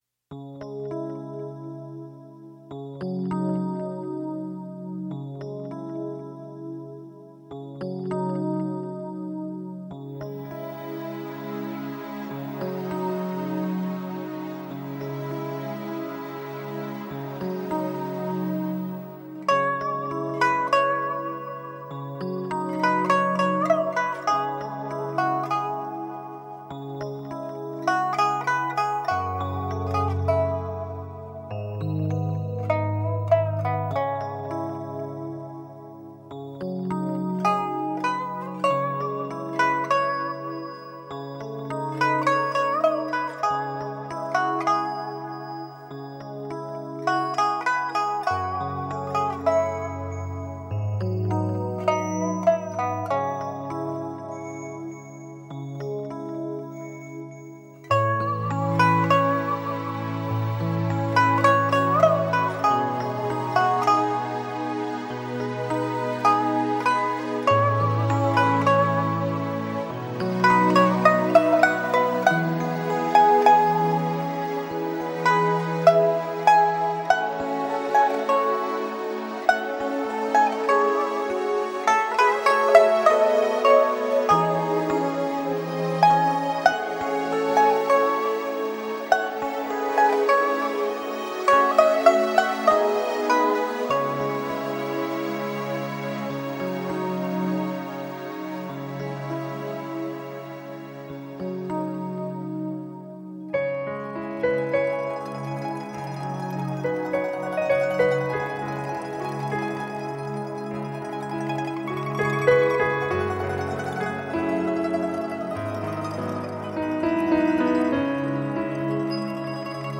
曲调悠扬婉转，既有江南丝竹的细腻，又有北方音乐的豪迈。
通过轮指、扫弦等技法
此曲旋律优美，意境深远，仿佛在诉说千年的故事。